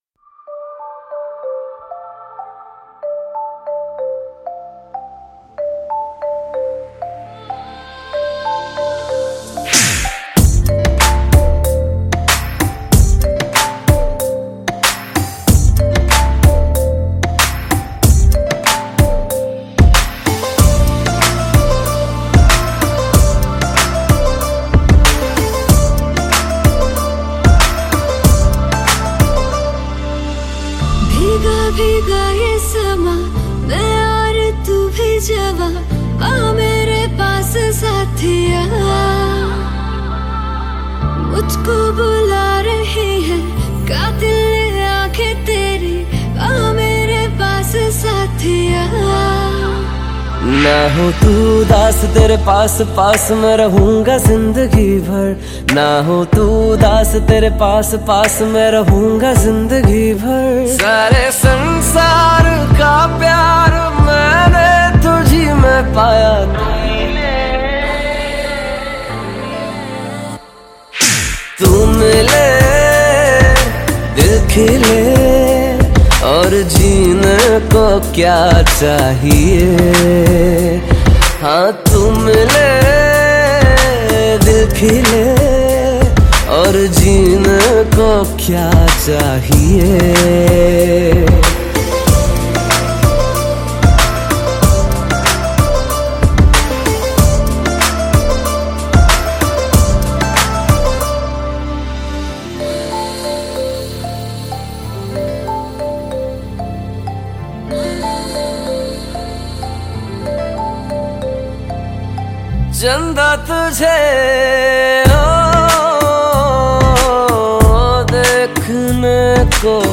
Indian POP